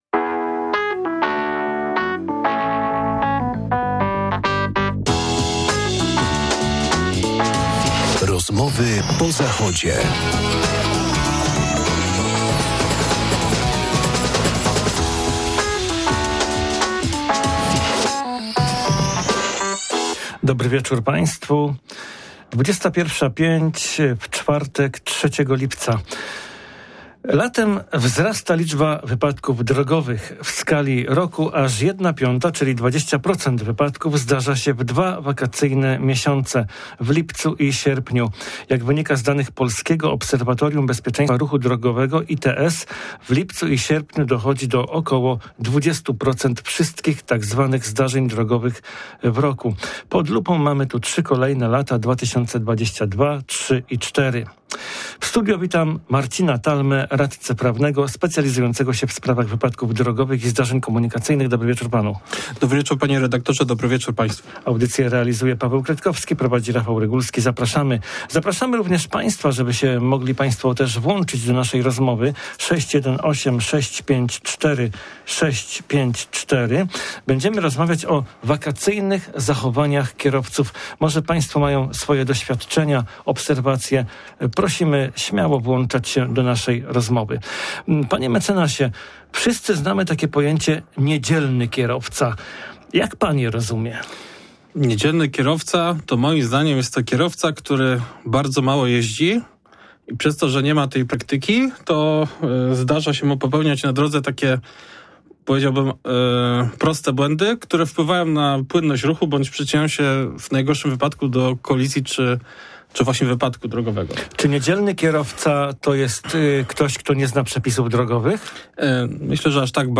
Do audycji aktywnie włączyli się Słuchacze, którzy chętnie podzielili się swoim doświadczeniem, wiedzą, obserwacją i dobrą radą.